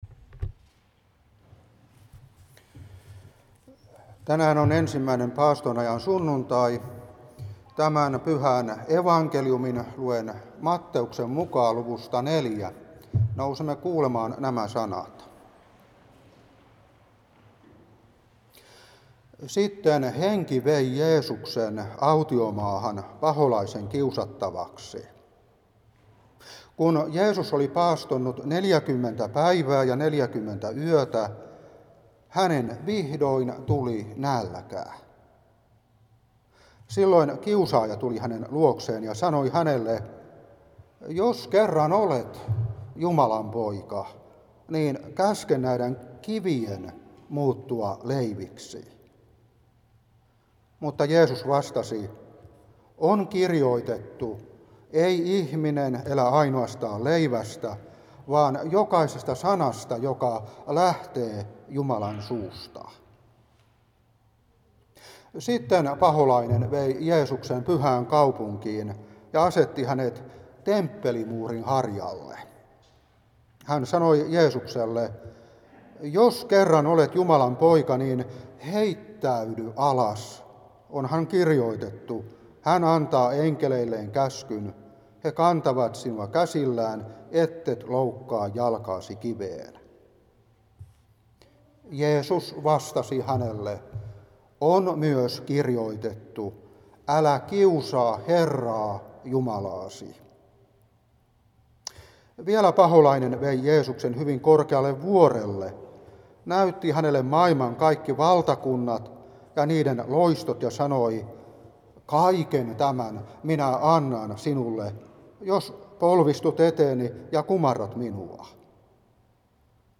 Saarna 2022-3.